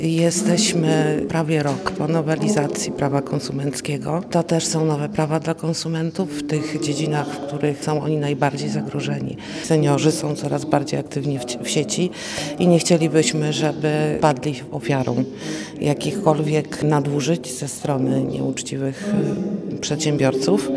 Miejski Rzecznik Konsumentów w Łodzi Izabela Skomerska, wyjaśnia, że uświadamianie dotyczy również zakupów internetowych.